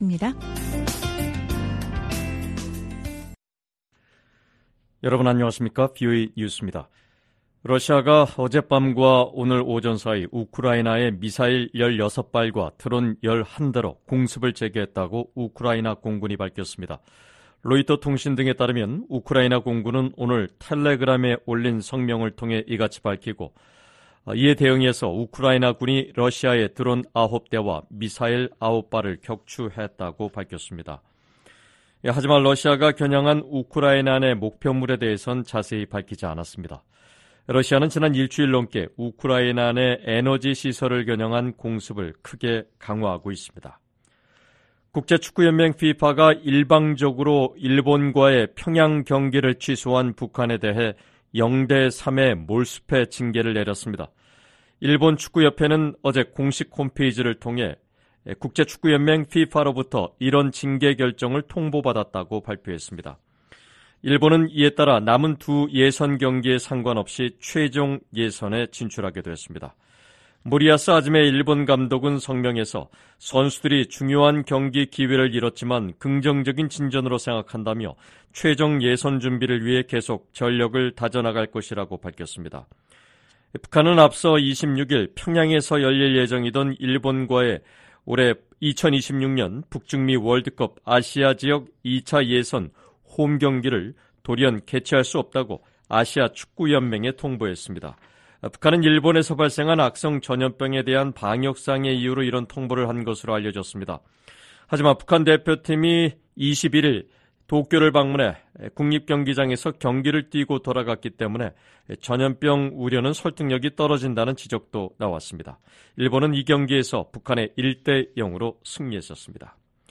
VOA 한국어 방송의 일요일 오후 프로그램 2부입니다.